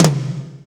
Index of /90_sSampleCDs/Northstar - Drumscapes Roland/TOM_Toms 1/TOM_F_R Toms x